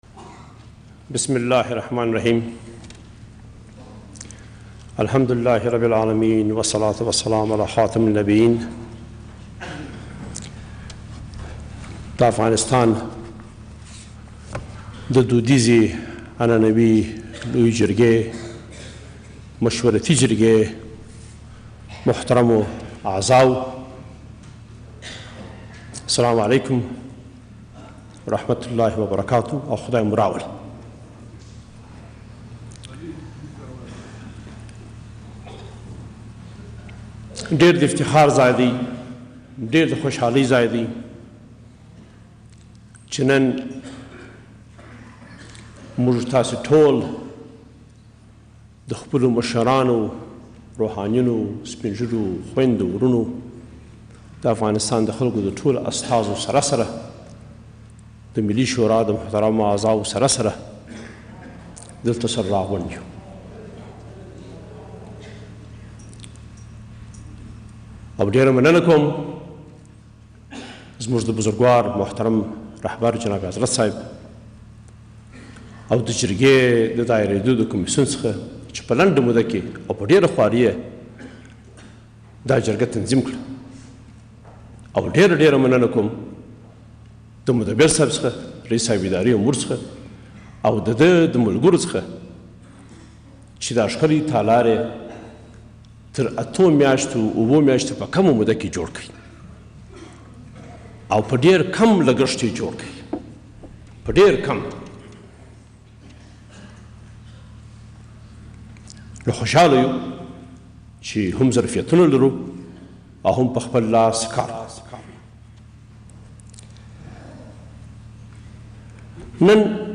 متن کامل بیانیهء حامد کرزی رییس جمهور افغانستان در لویهء جرگهء مورخ شانزدهم نوامبر 2011